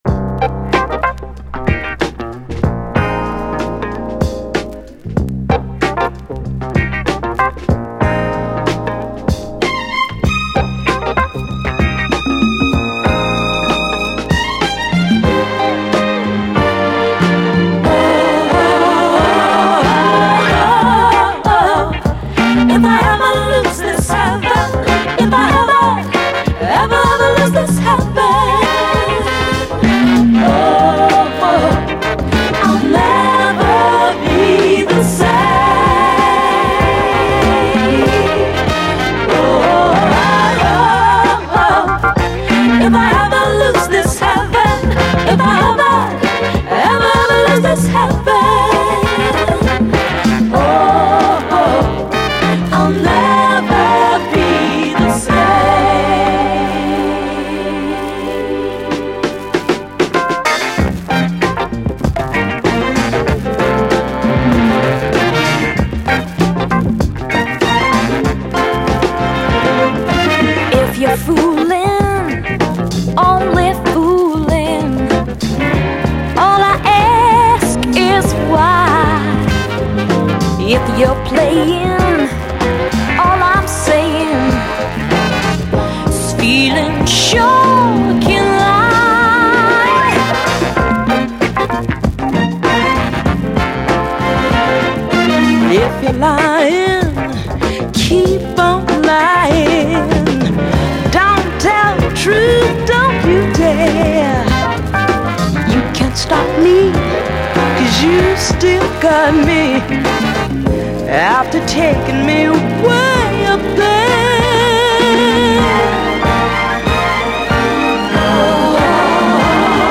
SOUL, 70's～ SOUL, 7INCH
70’Sメロウ・ソウル名曲